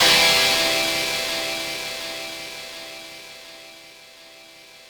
ChordC.wav